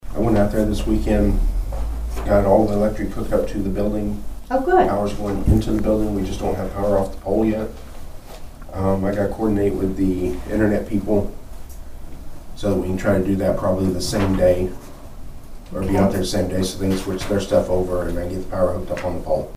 The Nowata County Commissioners met for a regularly scheduled meeting on Monday morning at the Nowata County Annex.
Chairman of the board Paul Crupper gave an update on the radio tower project.